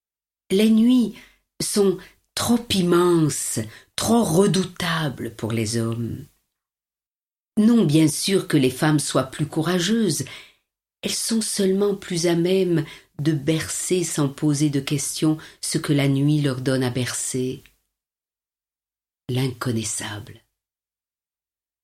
Toute la richesse de son humanité passionnée y est rassemblée, et, bonheur en plus, elle la lit à haute voix…